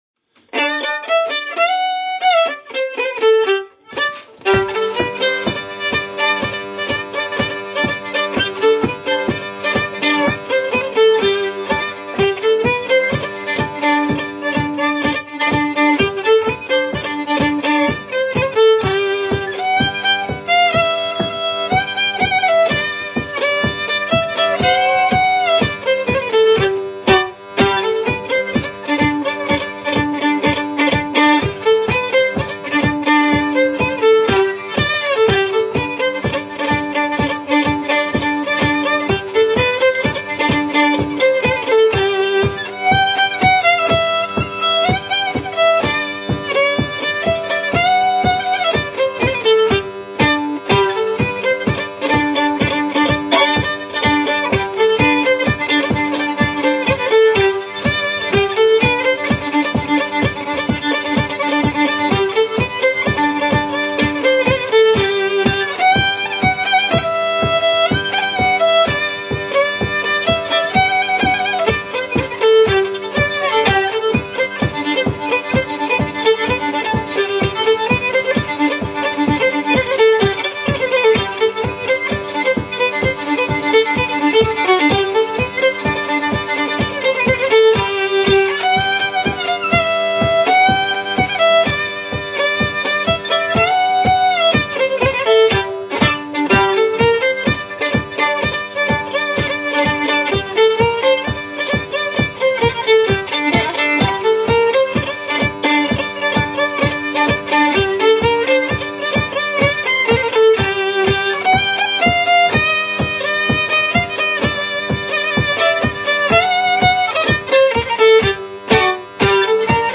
Below are links to mp3s of the FSC Country Dancing music as heard and used on camp.